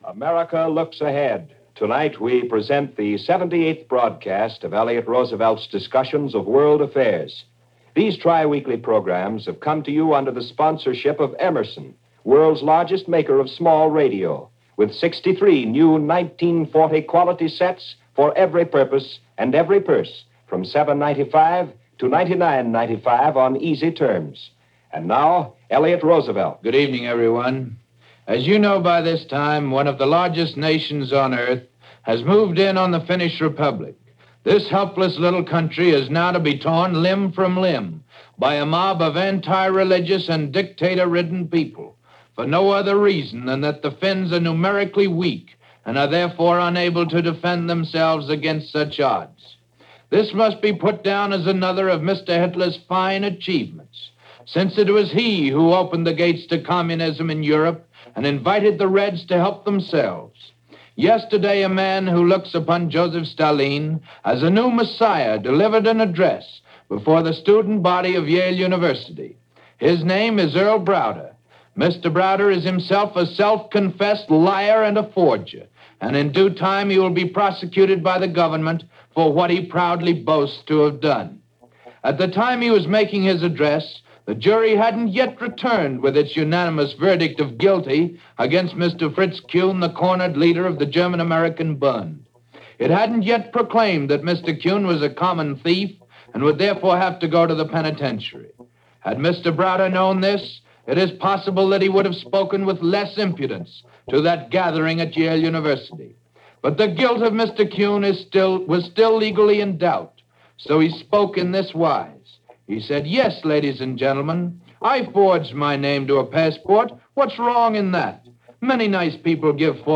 Finland Invaded - Soviets Cross Border - Helsinki Bombed - November 30, 1939 - news and Commentary from Elliot Roosevelt.
According to this news and analysis by Elliot Roosevelt, son of the President, the Soviet Union invaded the country earlier in the day, sending shockwaves throughout Europe and the rest of the world.